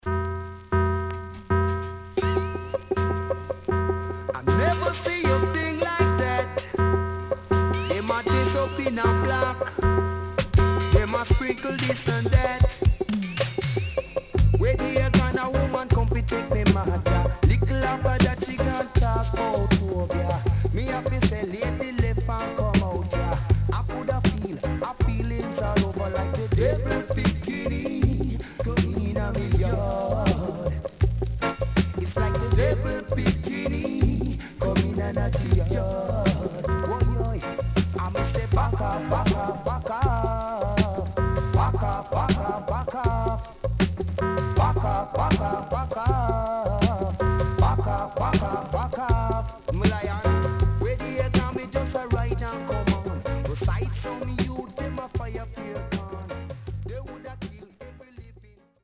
ダンスホール賛歌
SIDE A:少しチリノイズ入りますが良好です。